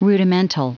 Prononciation du mot : rudimental
Entrez un mot en anglais, et nous le prononcerons pour vous.